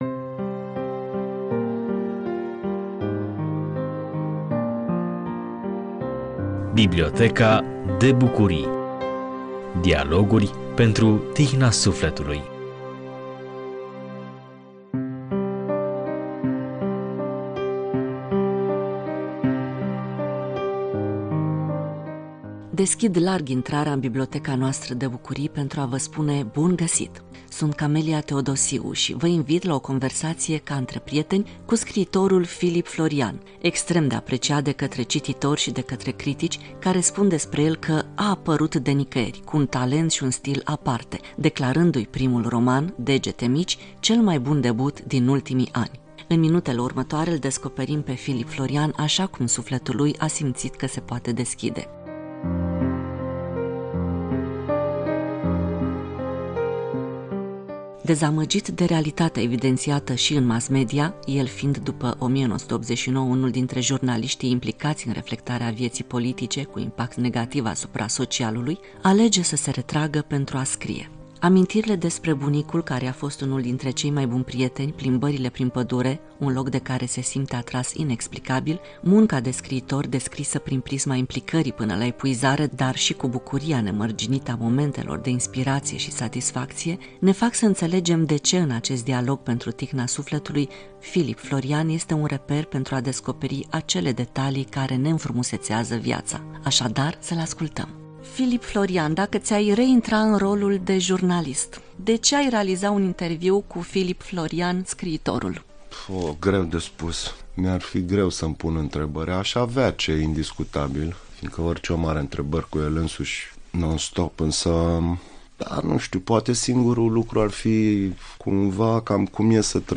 vă invit la o conversaţie, ca între prieteni, cu scriitorul Filip Florian